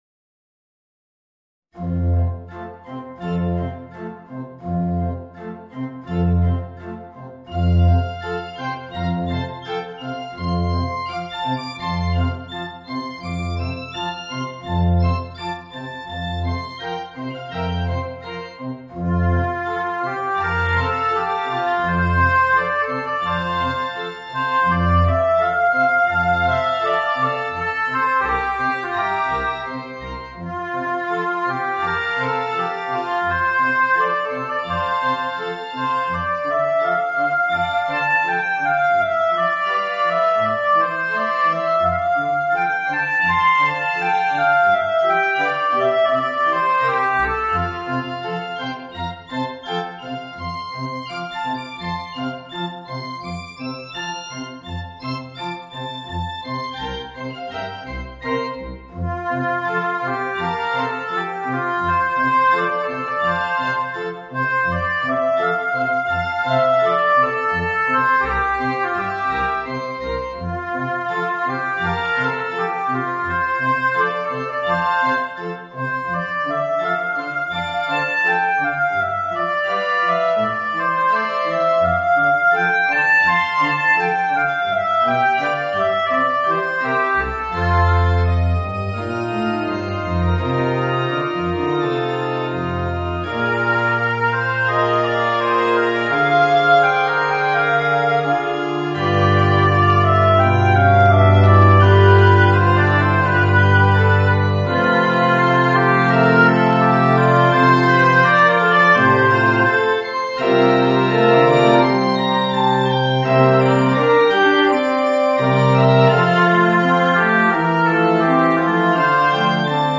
Voicing: Oboe and Piano